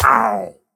Minecraft Version Minecraft Version 1.21.5 Latest Release | Latest Snapshot 1.21.5 / assets / minecraft / sounds / mob / pillager / hurt1.ogg Compare With Compare With Latest Release | Latest Snapshot
hurt1.ogg